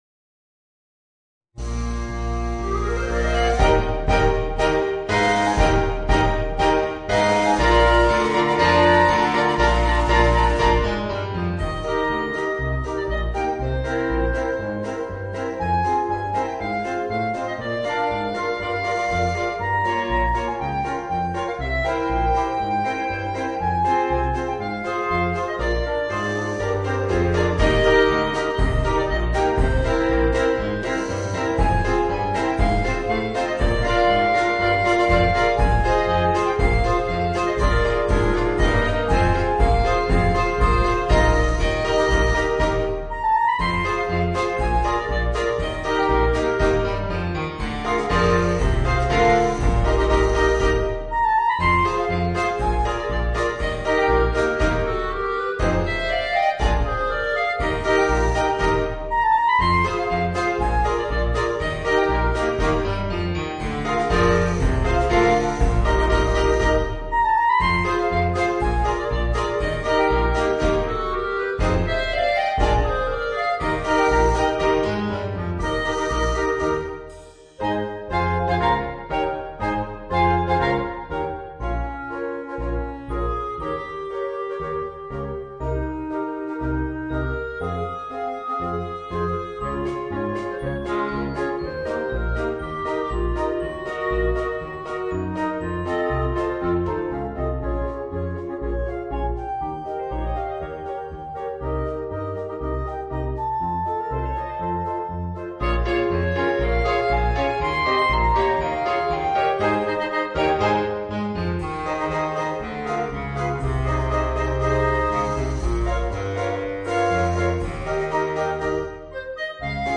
Voicing: 5 Clarinets and Rhythm Section